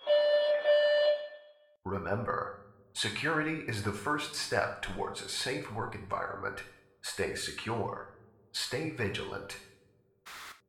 announcement2.ogg